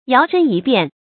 注音：ㄧㄠˊ ㄕㄣ ㄧ ㄅㄧㄢˋ